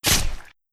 Melee Weapon Attack 27.wav